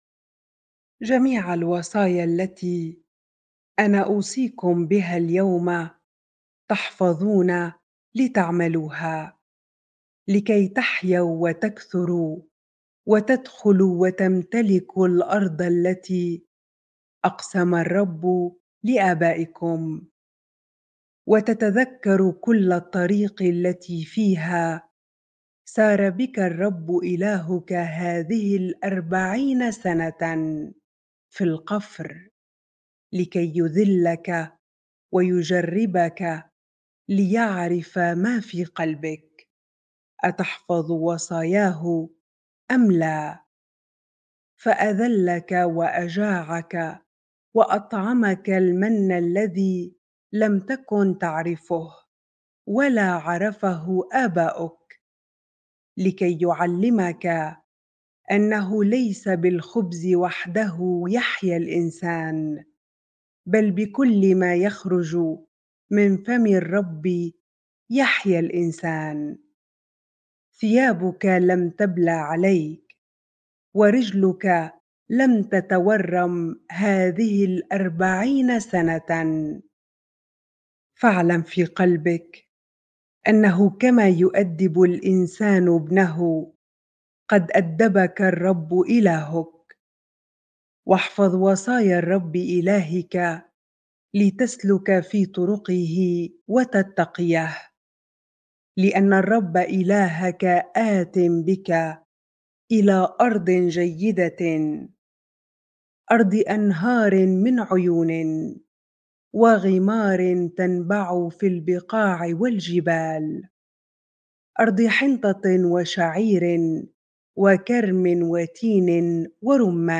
bible-reading-deuteronomy 8 ar